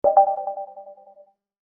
Elegant Business Alert – Minimalist Notification Sound Effect
Description: Elegant business alert – minimalist notification sound effect. Elevate your project with this professional, high-quality minimalist business alert. It enhances mobile apps, corporate notifications, and office UI seamlessly.
Elegant-business-alert-minimalist-notification-sound-effect.mp3